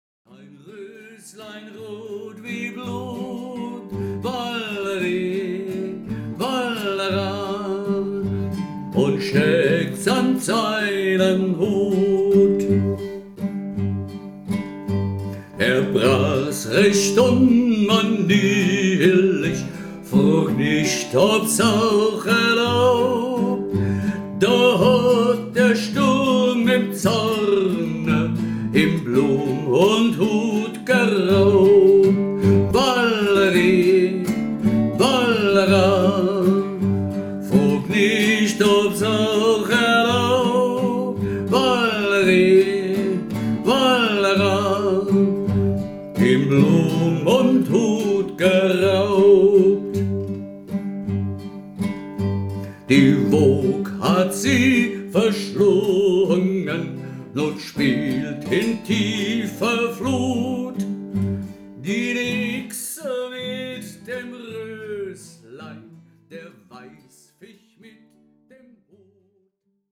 Ballade - 6/8
Die Melodie erhält dadurch merklich mehr Schwung.